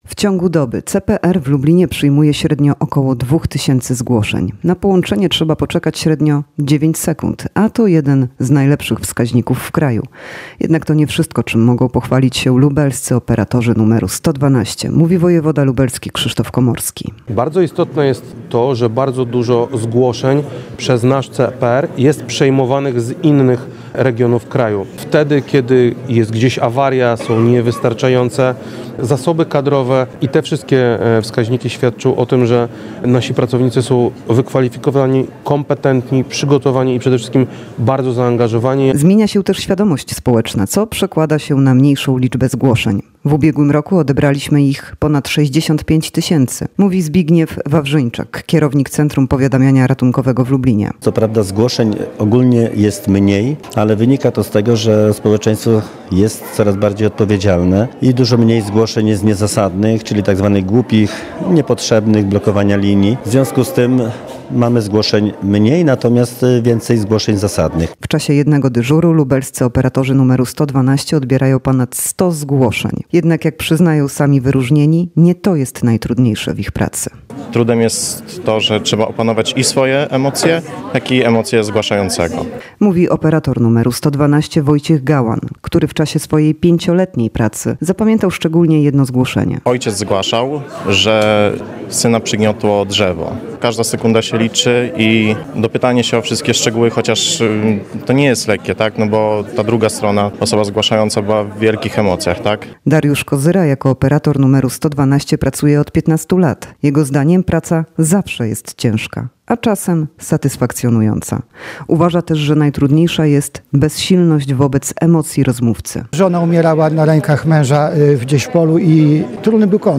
– Bardzo istotne jest to, że wiele zgłoszeń jest przejmowanych przez nasz CPR z innych regionów kraju, wtedy kiedy jest gdzieś awaria, są niewystarczające zasoby kadrowe – mówi wojewoda lubelski Krzysztof Komorski.